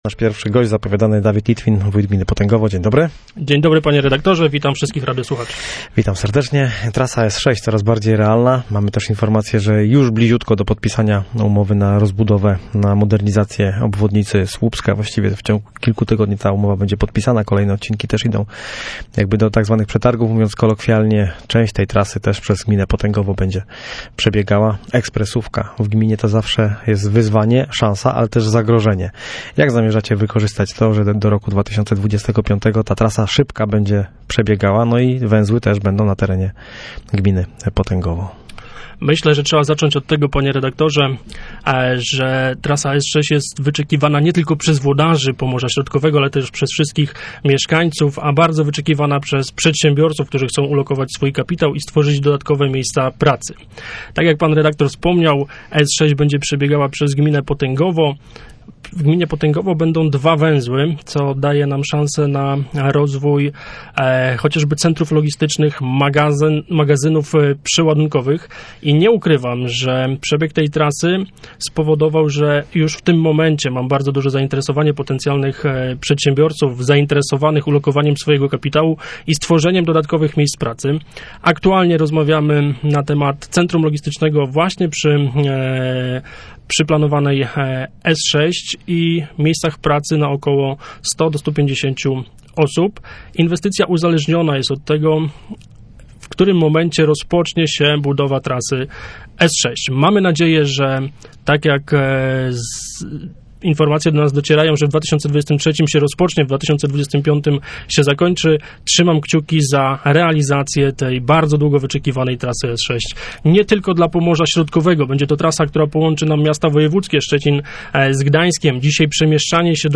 Dawid Litwin był gościem miejskiego programu Radia Gdańsk Studio Słupsk 102 FM.